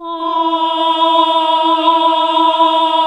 AAH E2 -R.wav